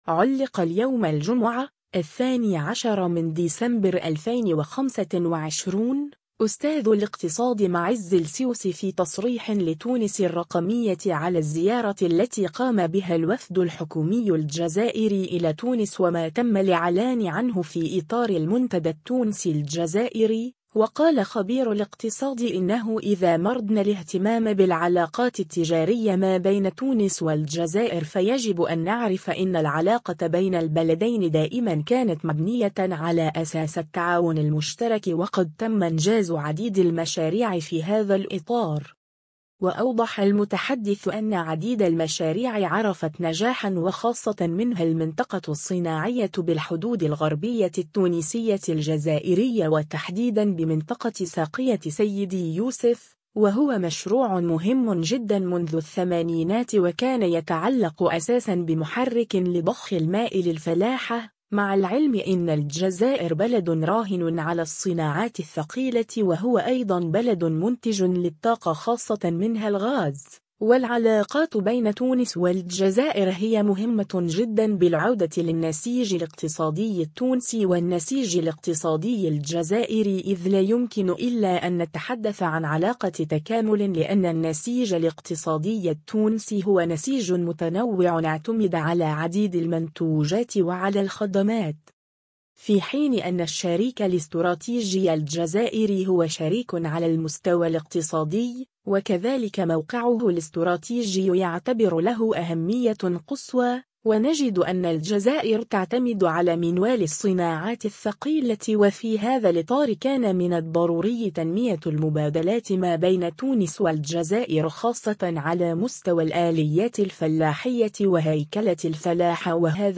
في تصريح لتونس الرّقمية